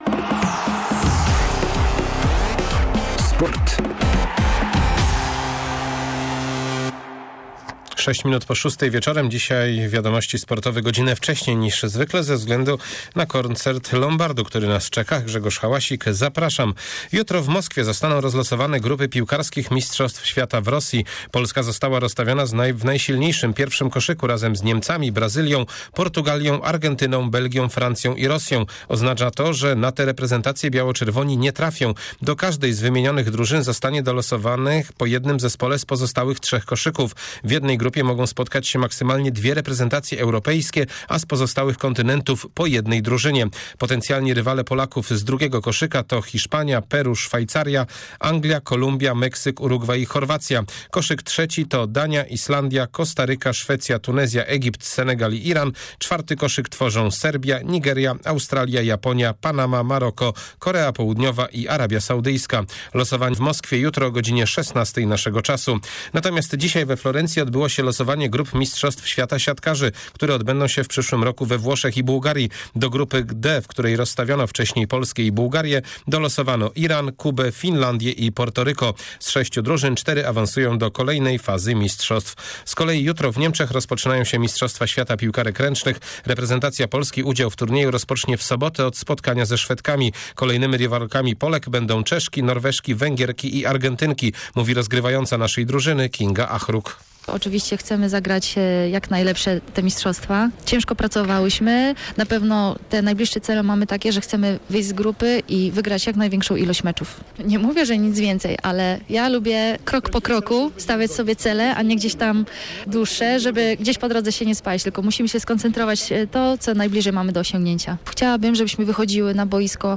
30.11 serwis sportowy godz. 18:05